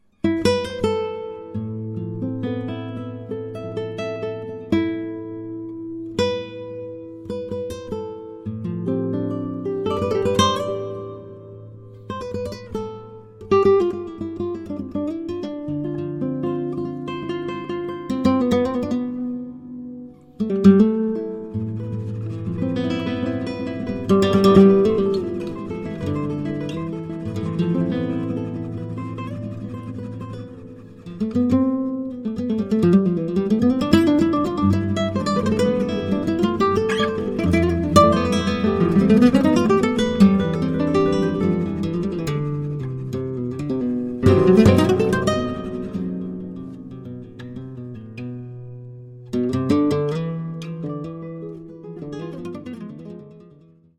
Multiple guitars
Multiple-guitars-1.mp3